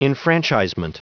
Prononciation du mot enfranchisement en anglais (fichier audio)
Prononciation du mot : enfranchisement